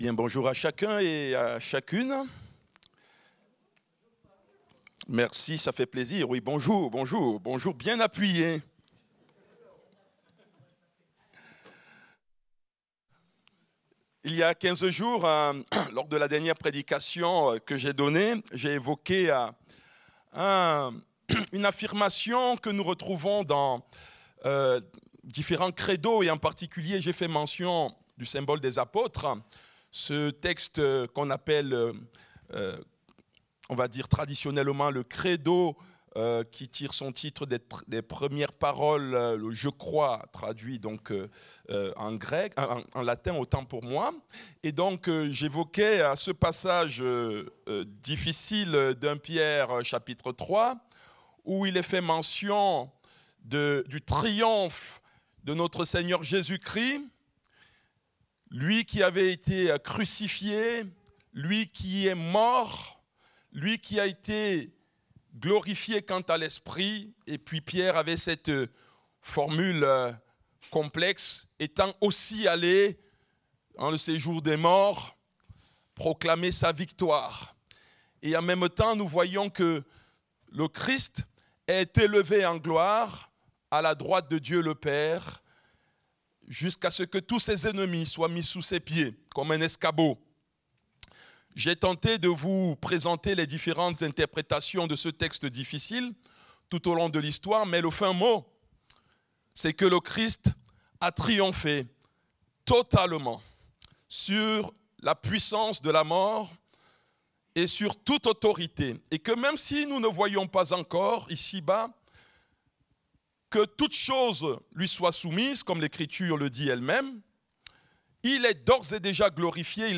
Culte du dimanche 25 mai 2025, prédication